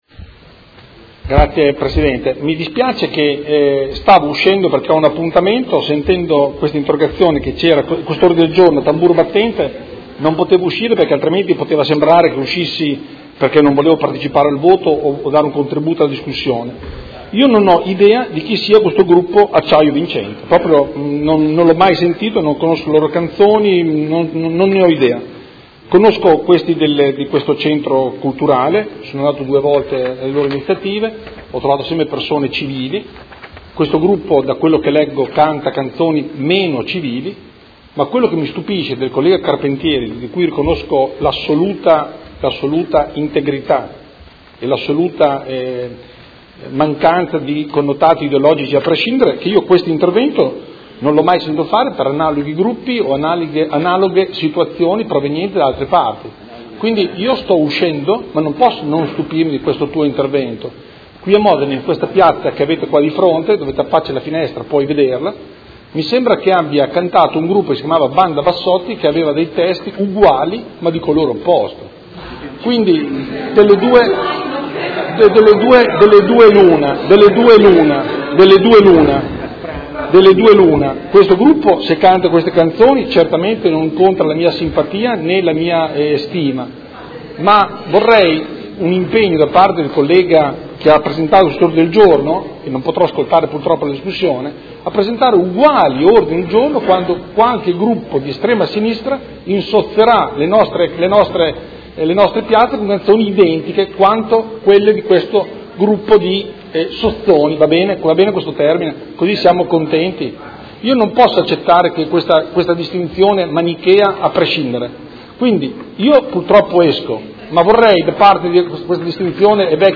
Dibattito su Ordine del Giorno firmato dai Consiglieri Carpentieri, Di Padova (P.D.), Scardozzi (M5S), Trande (Art.1- MDP), Campana (Per Me Modena), Montanini (CambiAMOdena) e Santoro (Idea-Popolo e Libertà) avente per oggetto: concerto del gruppo Acciaio Vincente presso il Circolo Terra dei Padri